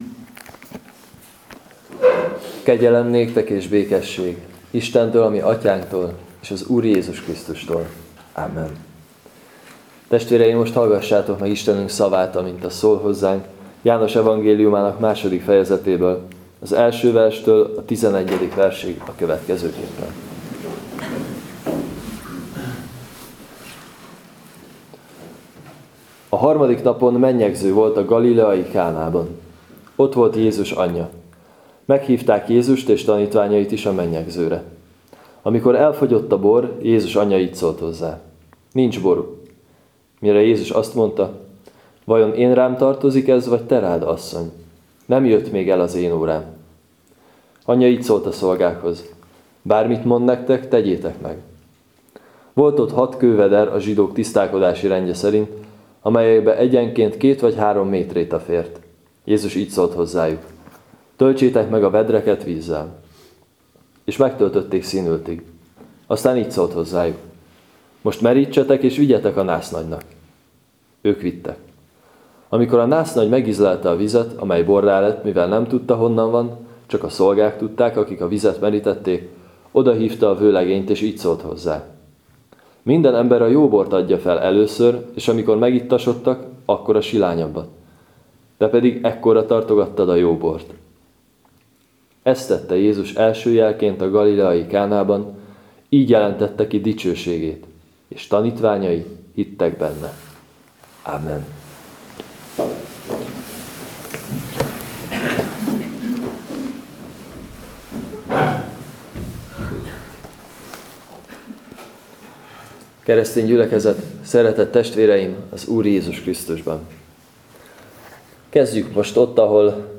01.15. Jn 2-1-11 igehirdetes.mp3 — Nagycserkeszi Evangélikus Egyházközség